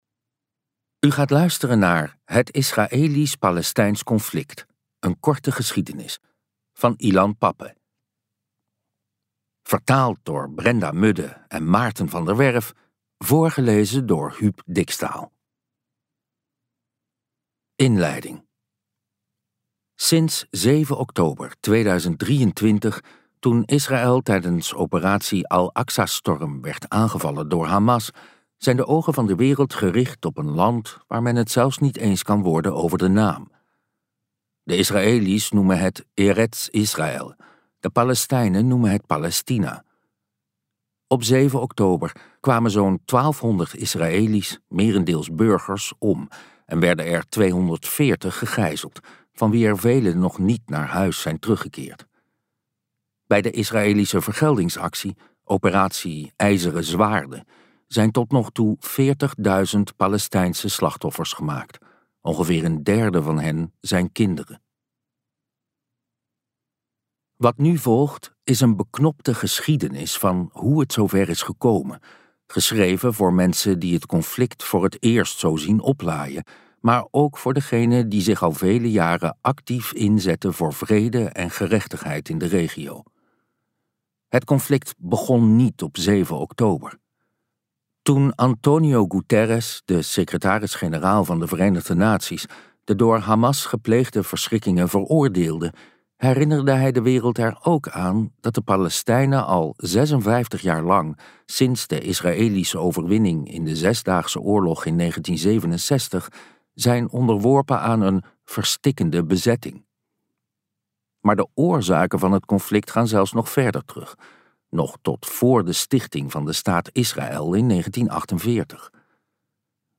Uitgeverij Omniboek | Het israelisch palestijns conflict luisterboek